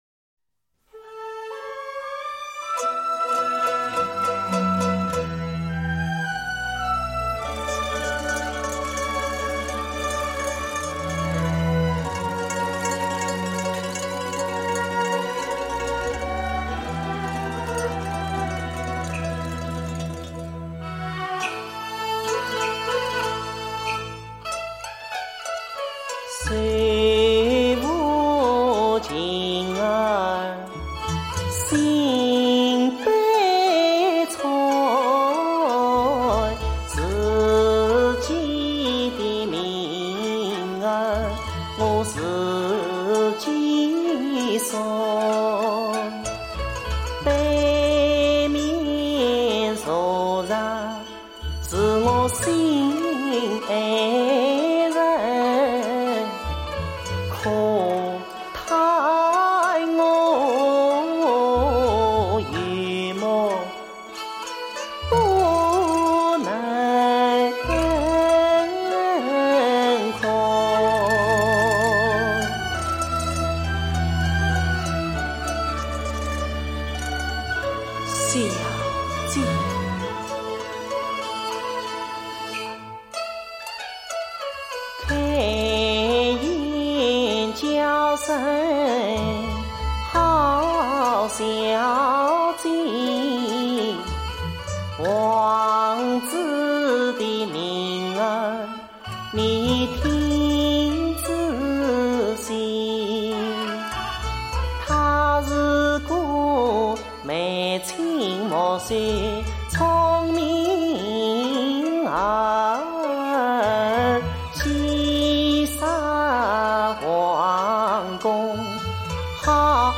越剧小生